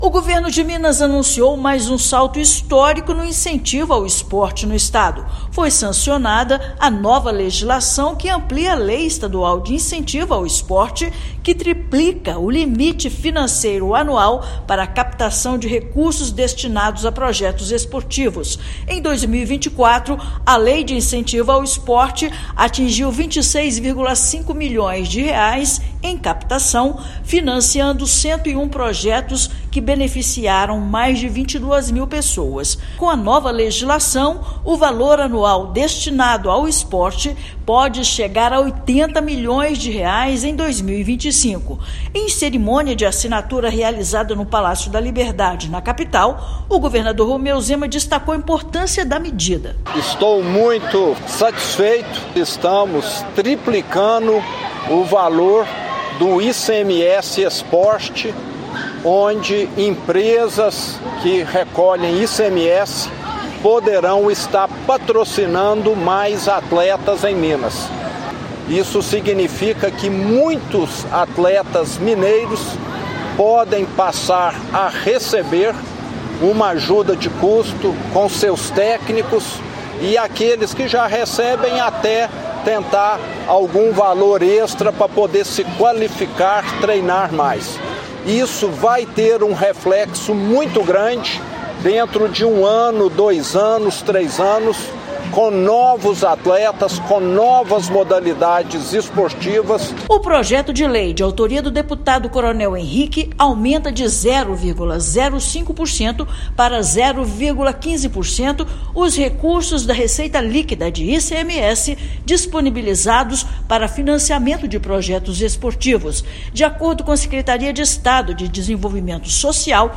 [RÁDIO] Governo de Minas triplica investimentos no esporte com nova Lei de Incentivo
Mudança na legislação aumenta limite de captação e reduz burocracias para atração de grande eventos esportivos no estado. Ouça matéria de rádio.